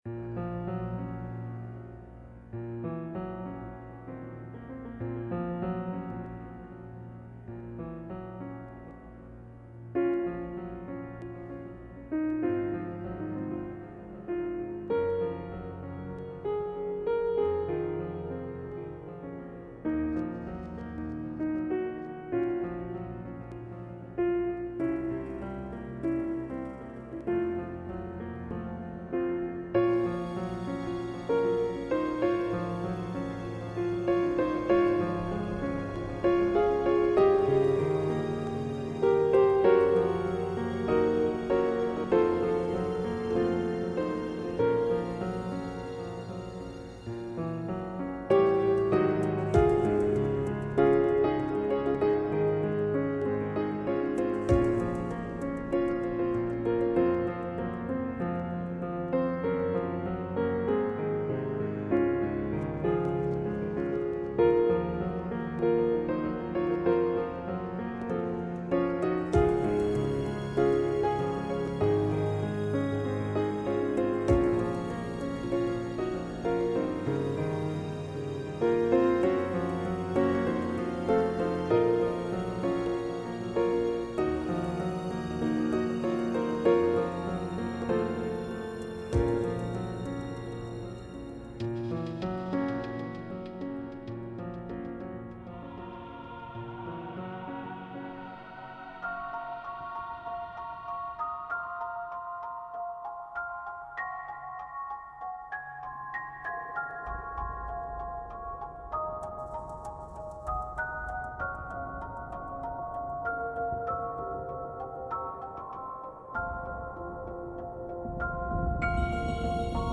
The song is based on Chopin's funeral march, and was supposed to be Veloce's theme before the story changed a lot!